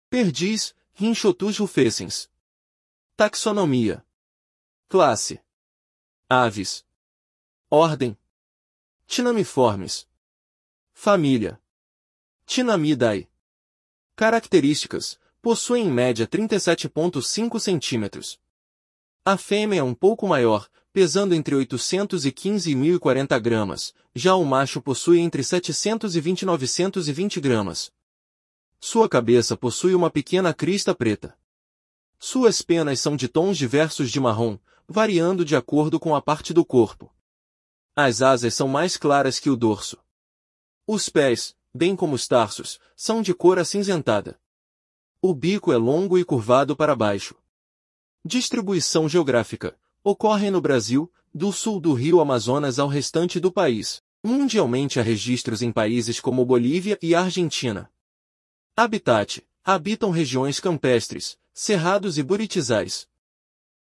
Perdiz (Rhynchotus rufescens)